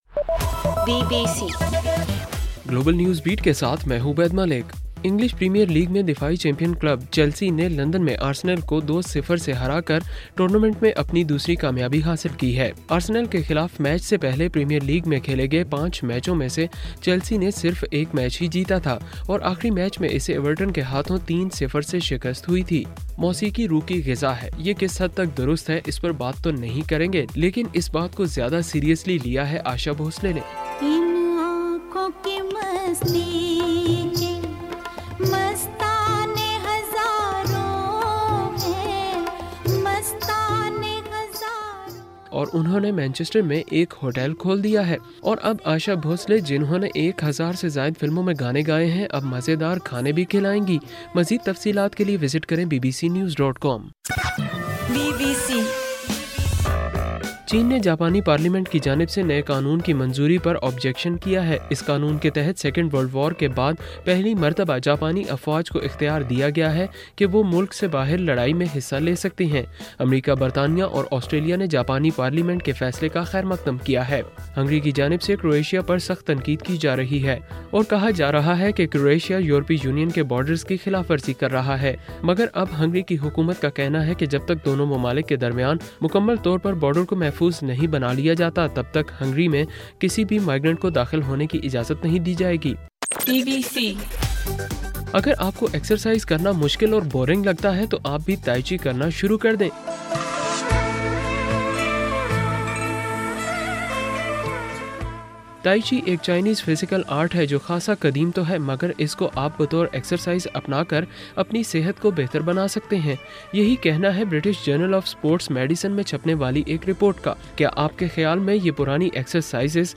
ستمبر 19: رات 10 بجے کا گلوبل نیوز بیٹ بُلیٹن